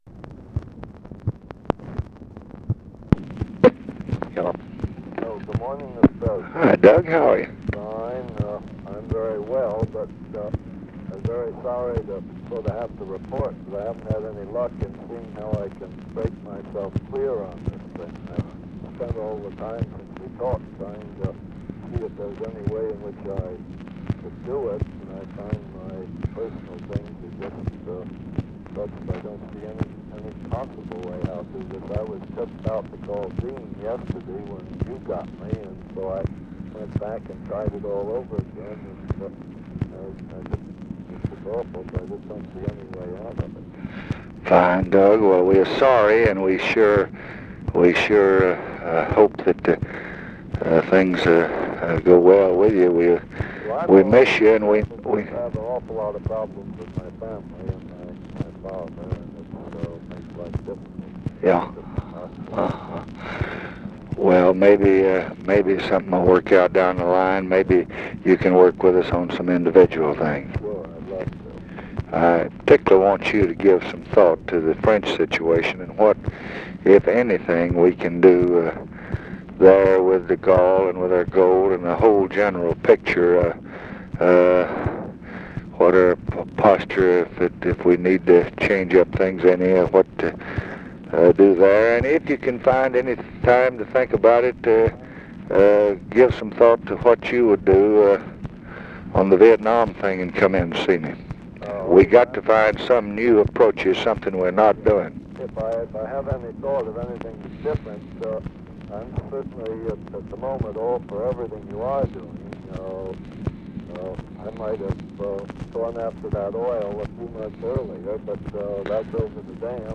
Conversation with DOUGLAS DILLON, August 9, 1966
Secret White House Tapes